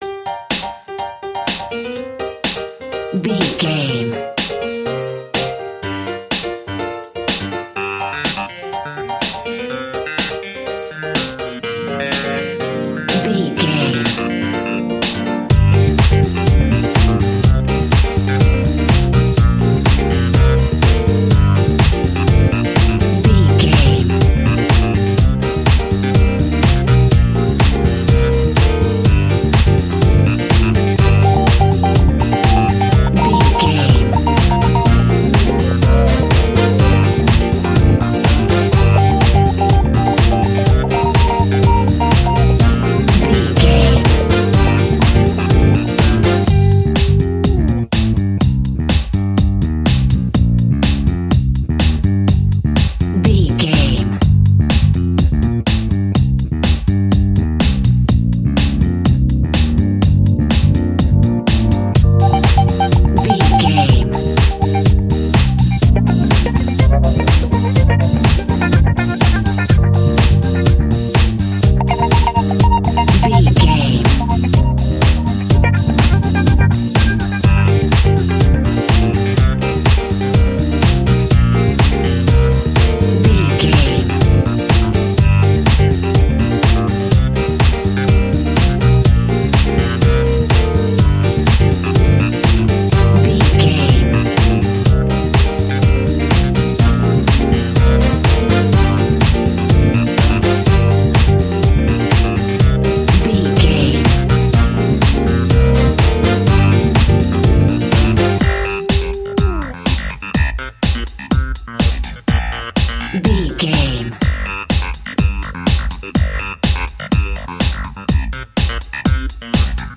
Top 40 Funky Dance Music.
Aeolian/Minor
uplifting
lively
energetic
smooth
groovy
drums
electric piano
electric guitar
horns
bass guitar
funky house
nu disco
upbeat